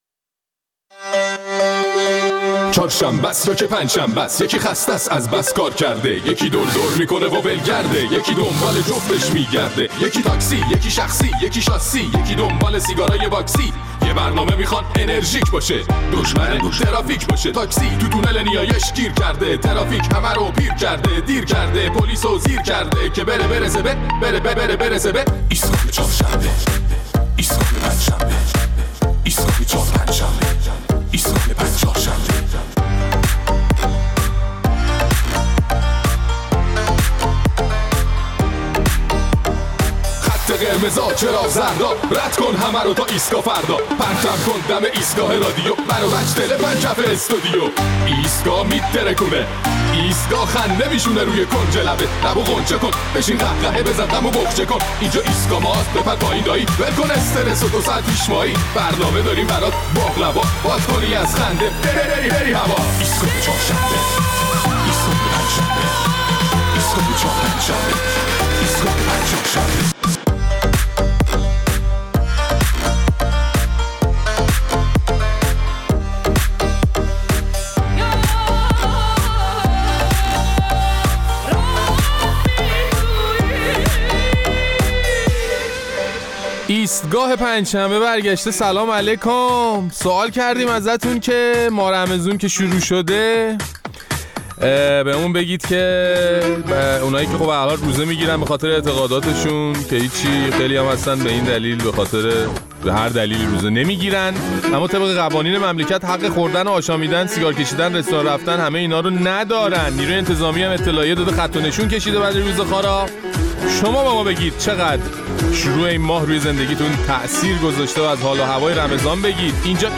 در این برنامه ادامه پاسخهای مخاطبین‌مان را درباره حال و هوای ماه رمضان و برخوردهایی که با پدیده موسوم به «روزه‌خواری» می‌شود می‌شنویم.